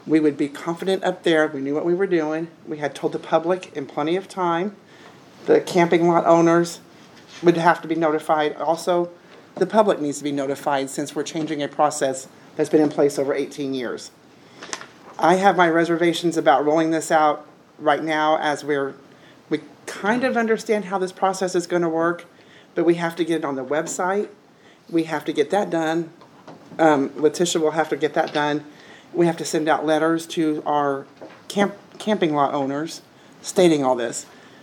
But, speaking during this past Monday’s special meeting, City Clerk Carla Huhn says they would like to have a little more time to get everything ready before they change to a new system.